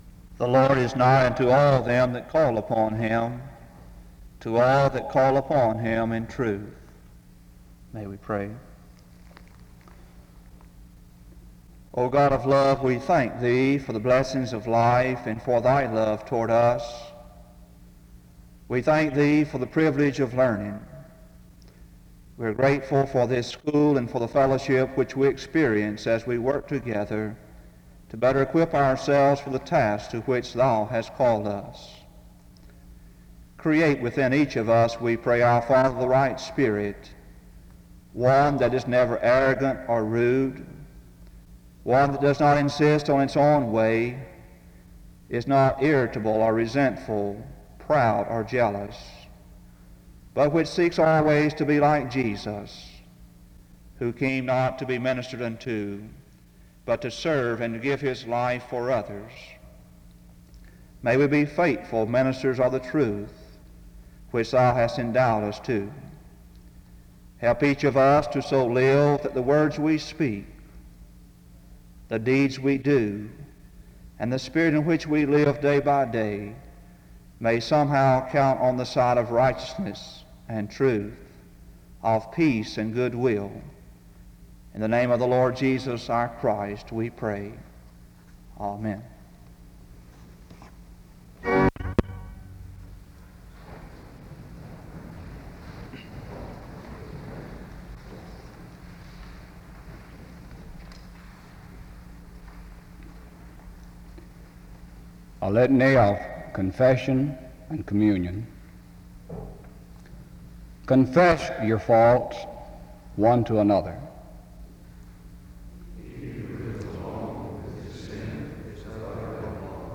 The service starts with an opening scripture reading and prayer from 0:00-1:29. A responsive reading takes place from 1:45-5:37. 1 John 1:3-7 is read 5:53-7:06. The theme of the message was on the topic of fellowship.
A closing prayer is offered from 19:07-19:47.